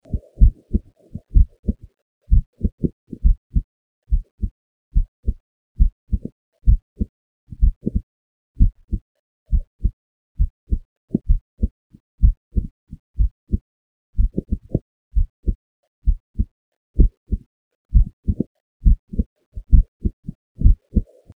Listen to the signal post algorithm: